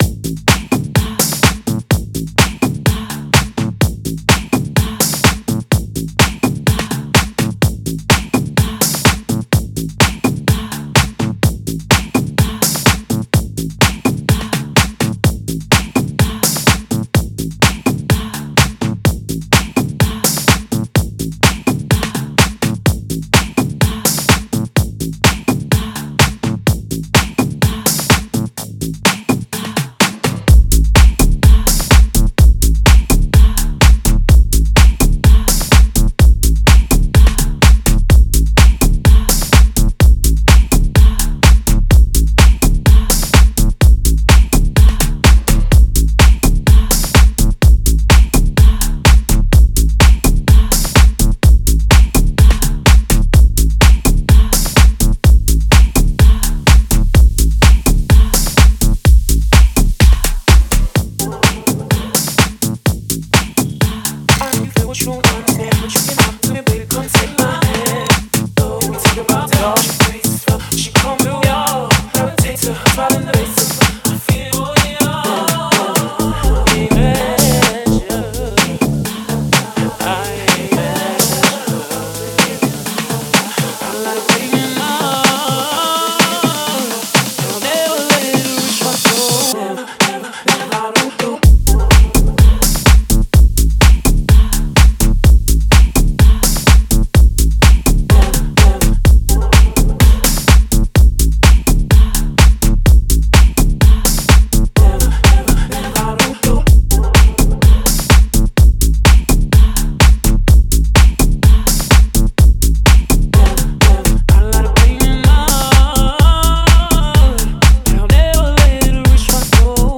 minimal-tech and house